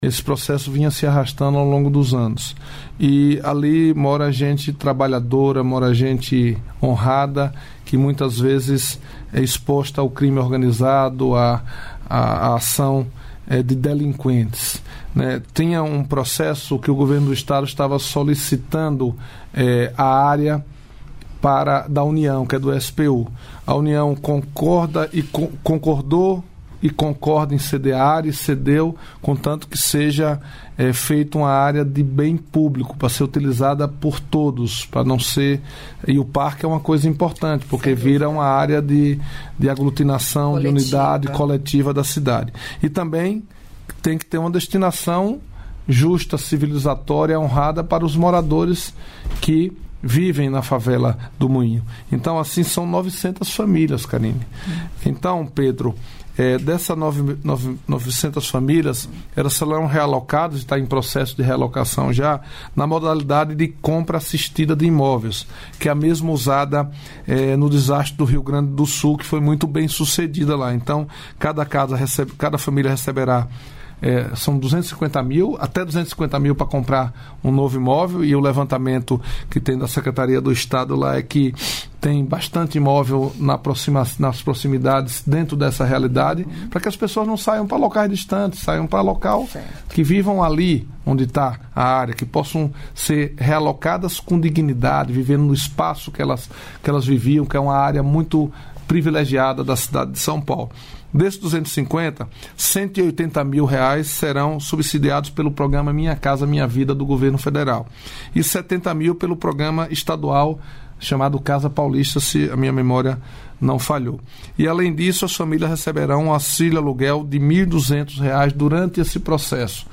Trecho da participação do ministro da Secretaria-Geral da Presidência da República, Márcio Macêdo, no programa "Bom Dia, Ministro" desta quarta-feira (18), nos estúdios da EBC em Brasília (DF).